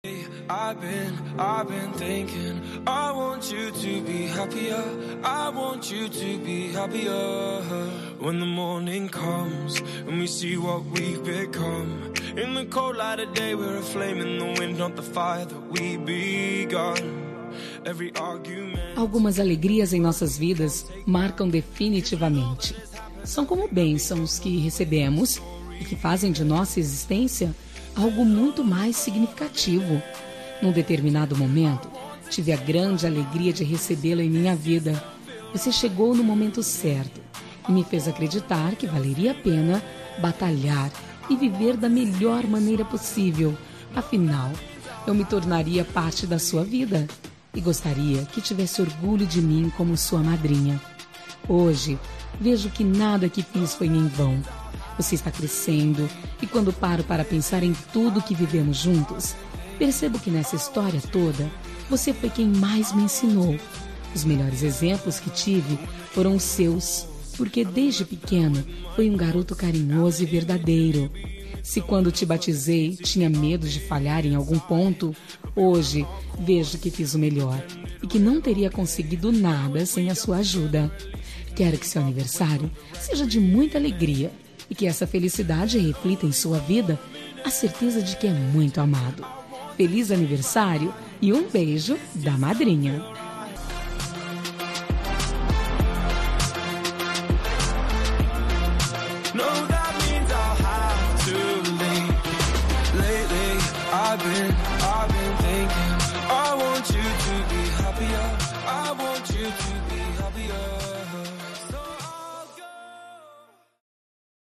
Aniversário de Afilhado – Voz Feminina – Cód: 2353